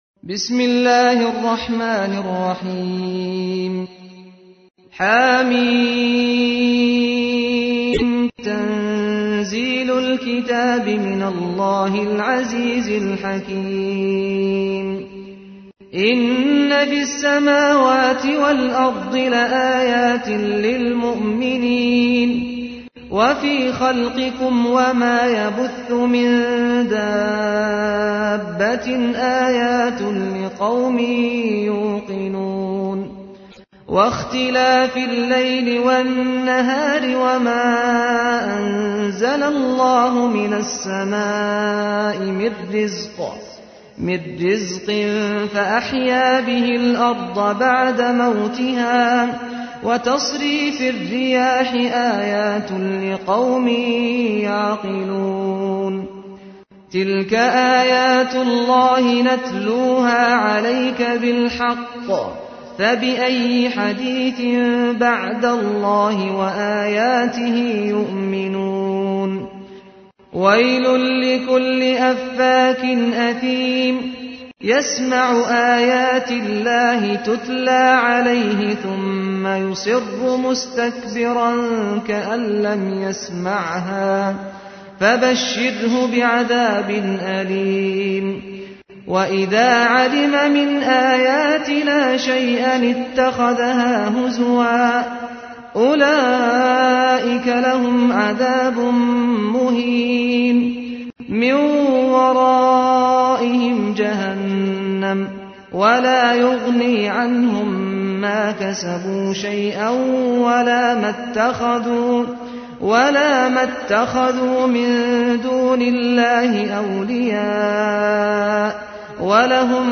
تحميل : 45. سورة الجاثية / القارئ سعد الغامدي / القرآن الكريم / موقع يا حسين